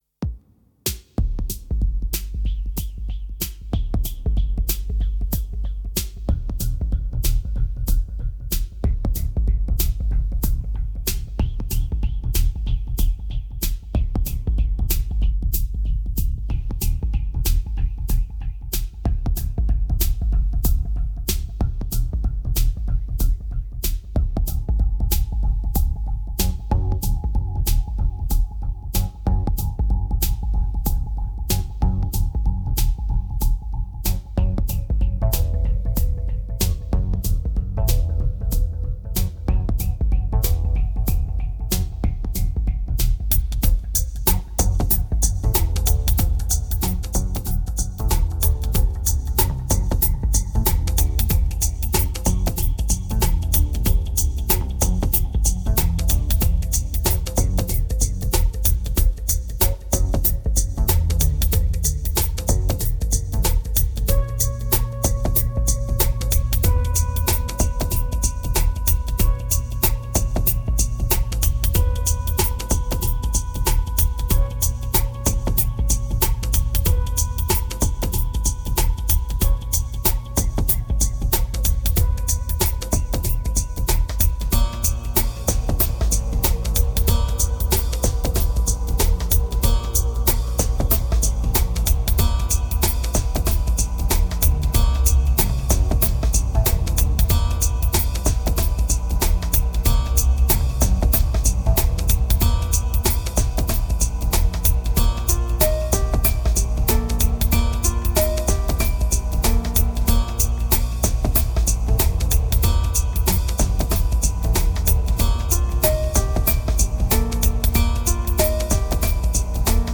1785📈 - -11%🤔 - 94BPM🔊 - 2010-04-18📅 - -483🌟